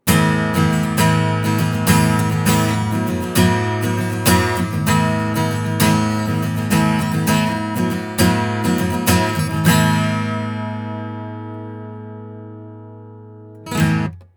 コンデンサー・マイクです。
低域を拾いづらいため、基本抜けの良いキラキラサウンドになりますね！
実際の録り音
アコースティック・ギター（ストローク）
451-アコギ.wav